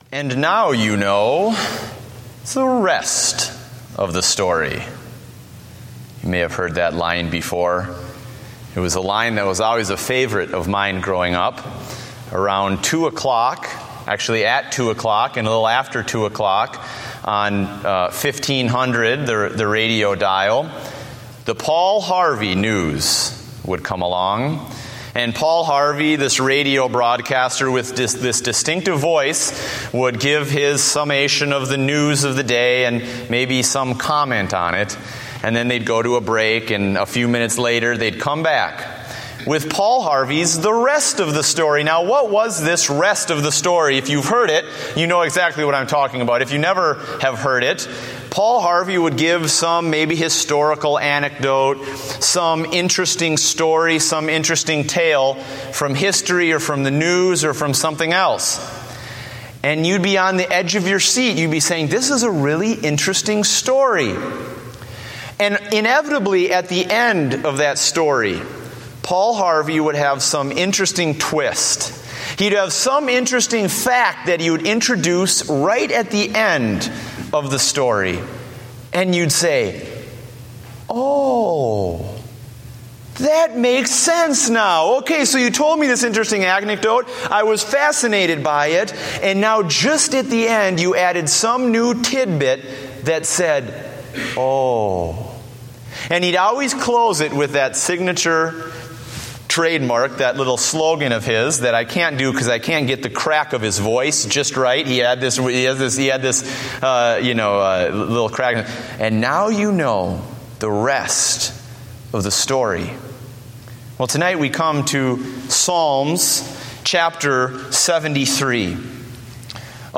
Date: August 10, 2014 (Evening Service)